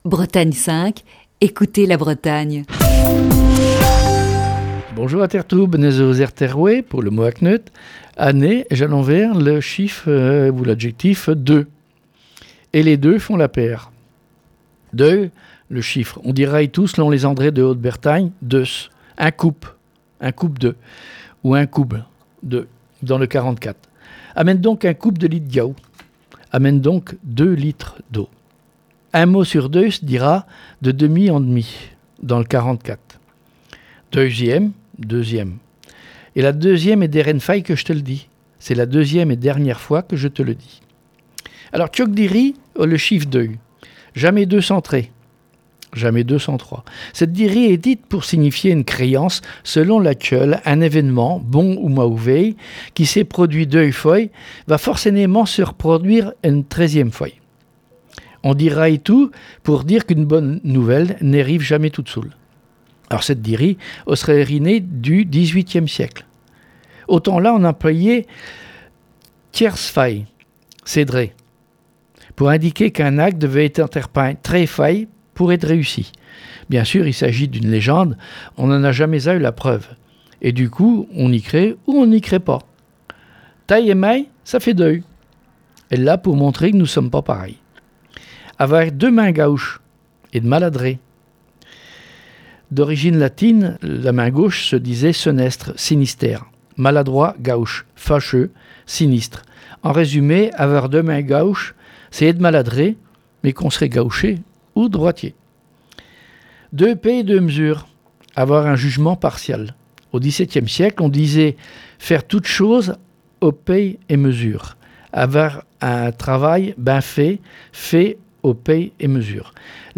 Chronique du 7 septembre 2021.